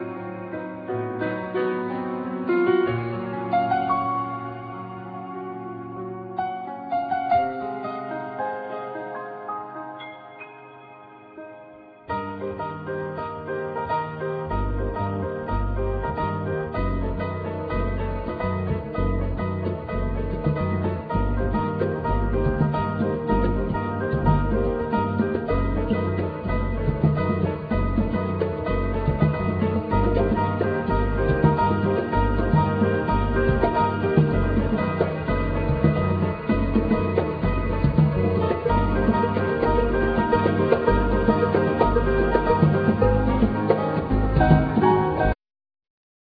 Bass,Piano
Hammond Organ
Electric Bass
Oud
Percussion,Vocals